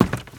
High Quality Footsteps
STEPS Wood, Creaky, Run 13.wav